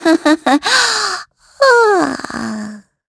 Gremory-Vox_Happy4_kr.wav